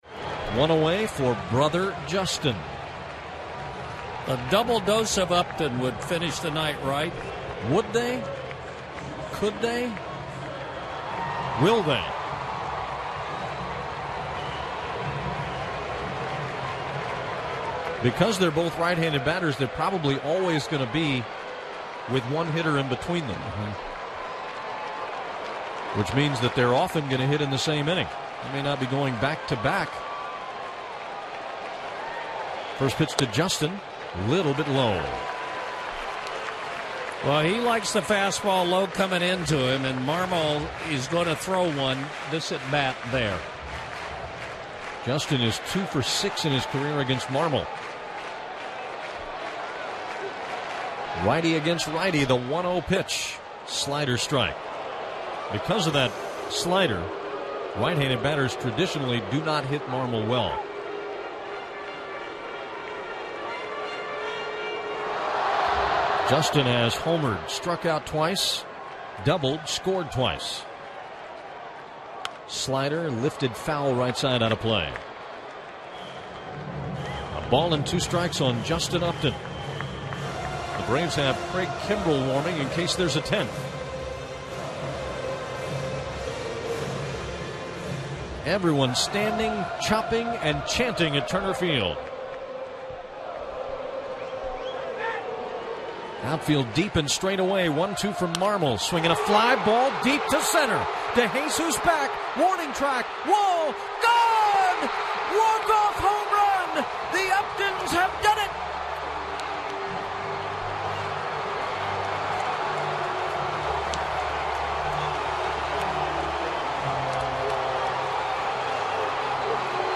Don Sutton with the call.